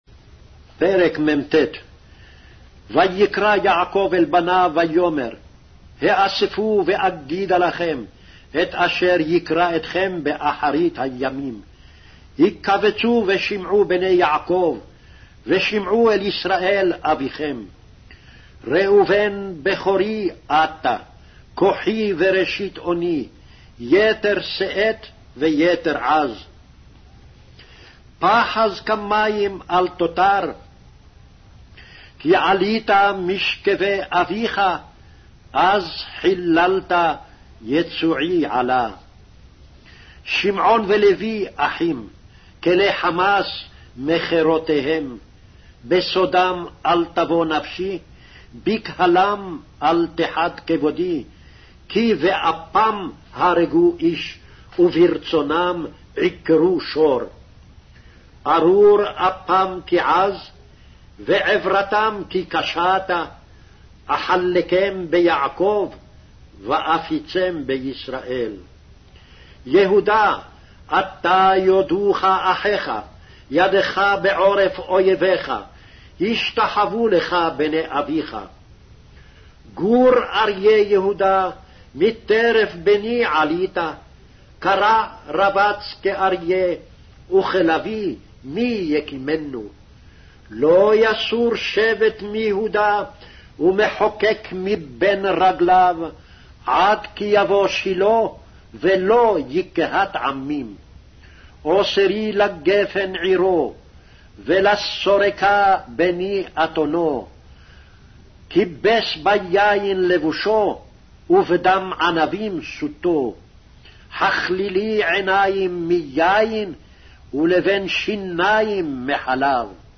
Hebrew Audio Bible - Genesis 22 in Ervta bible version